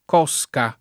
[ k 0S ka ]